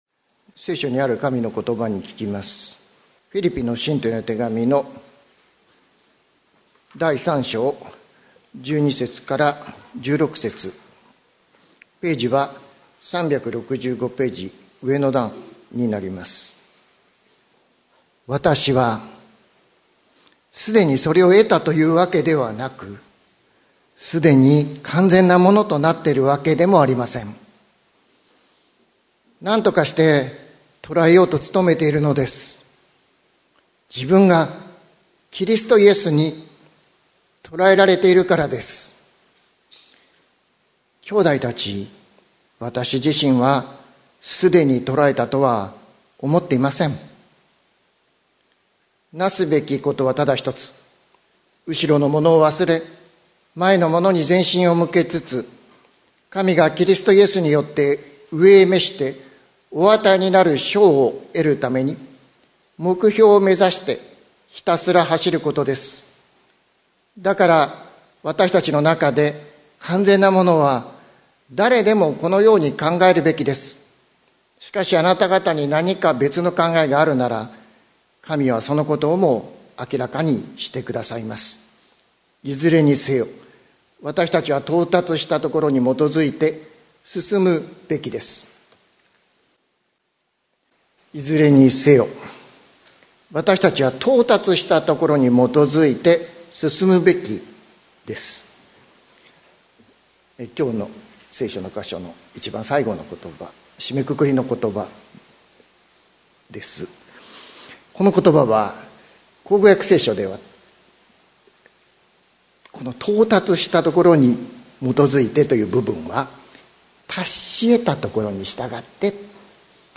2024年06月23日朝の礼拝「後ろのものを忘れ、前へ」関キリスト教会
説教アーカイブ。